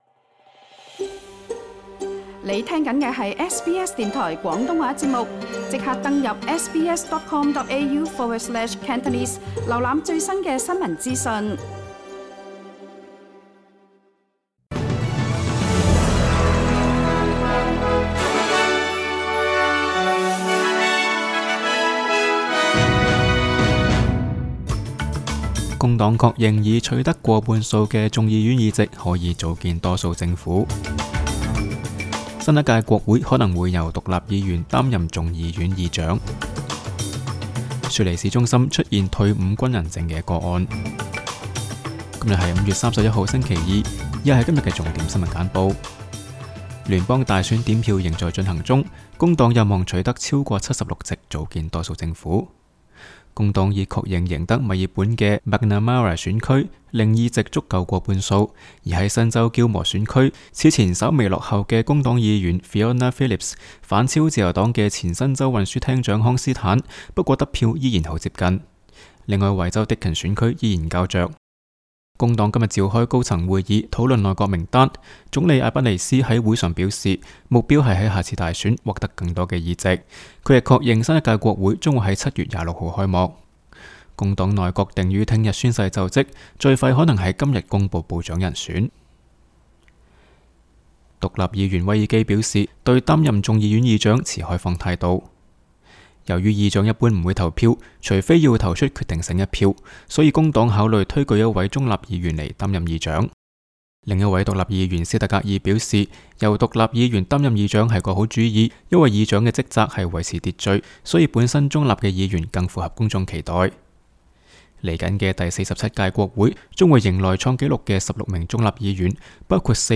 SBS 廣東話節目新聞簡報 Source: SBS Cantonese